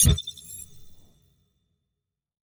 Deep HiTech UI Sound 4.wav